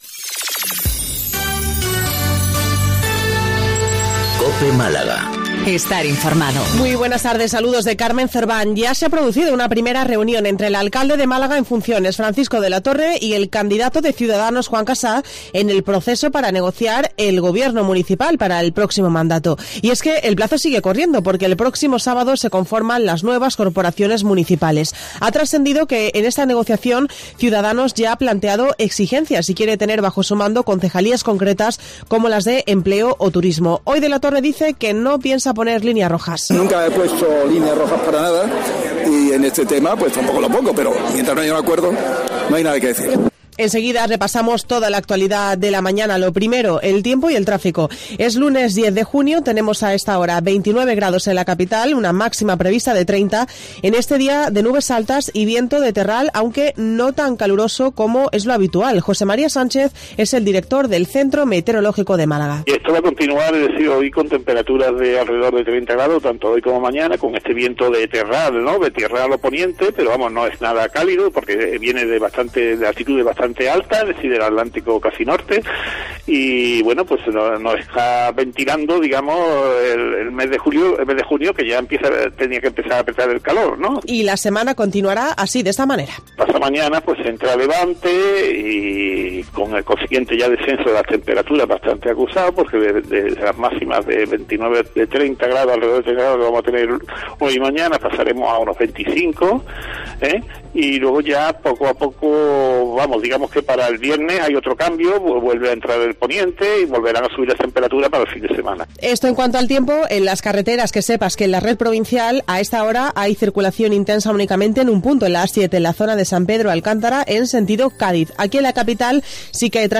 Hoy en 'Mediodía en COPE MÁS Málaga' escuchas al alcalde de la capital en funciones, Francisco de la Torre.